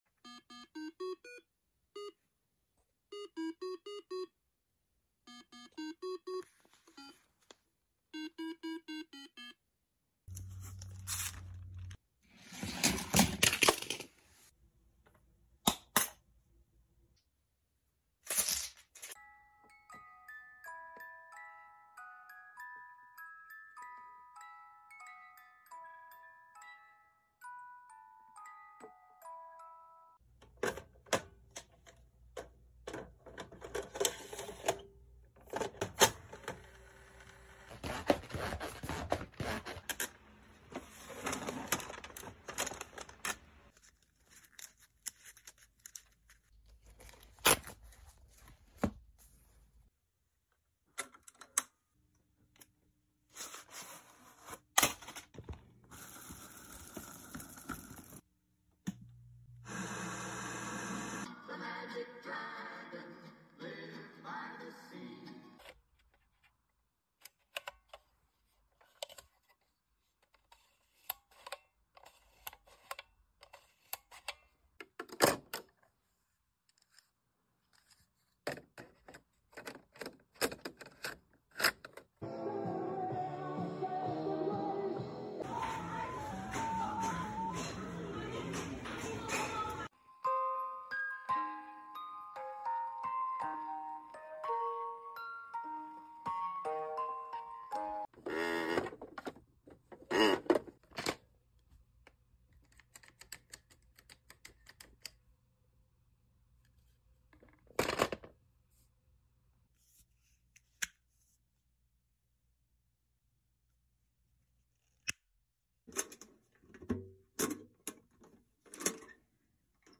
Some 80s sounds recorded in sound effects free download
Some 80s sounds recorded in my retro room.